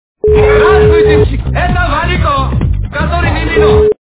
» Звуки » Люди фразы » Здравствуйте, - это Валико, который Мимино
При прослушивании Здравствуйте, - это Валико, который Мимино качество понижено и присутствуют гудки.